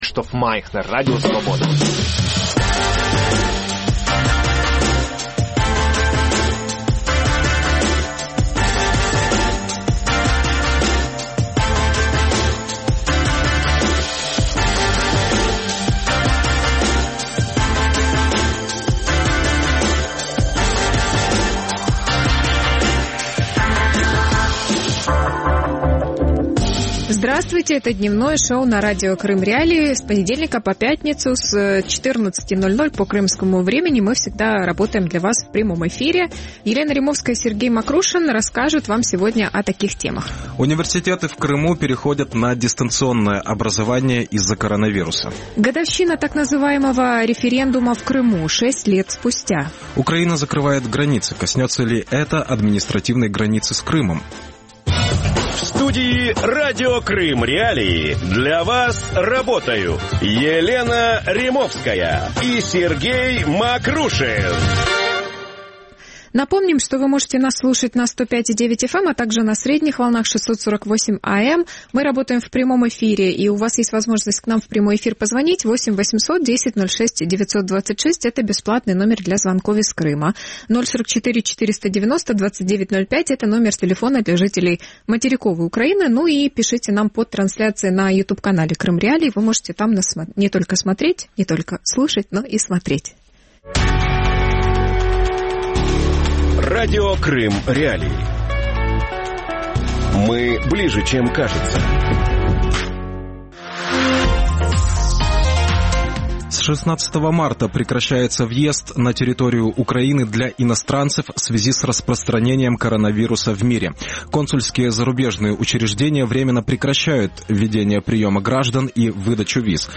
Крым. 6 лет после «референдума» | Дневное ток-шоу